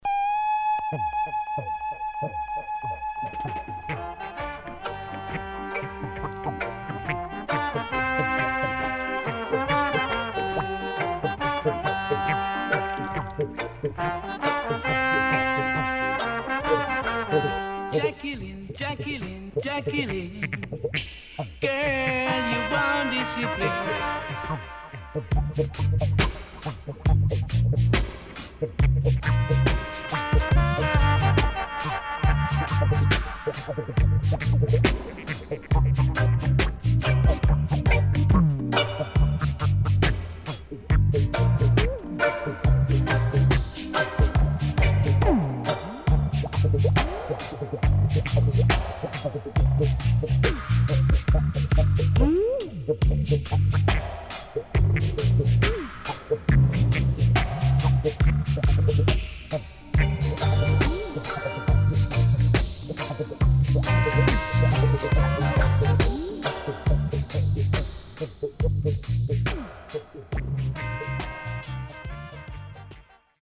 HOME > REISSUE [DANCEHALL]